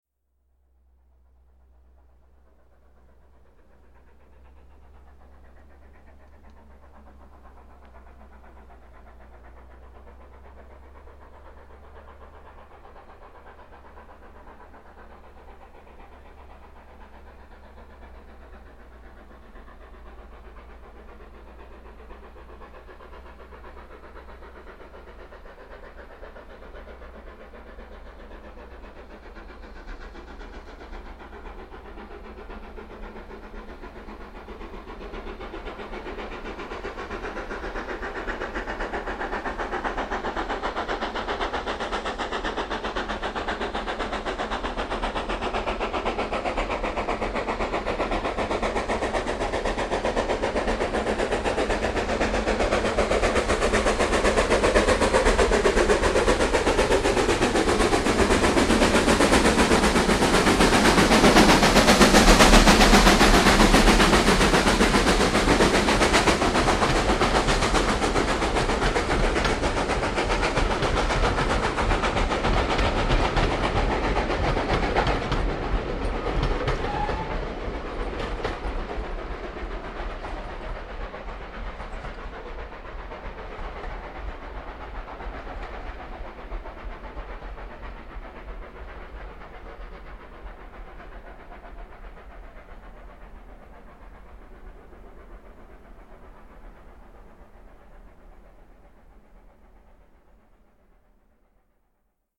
Diese Fahrweise war allerdings schon außergewöhnlich laut:
80135 ebenfalls im Einschnitt bei Beck Hole, um 16:57h am 12.08.2000.   Hier anhören: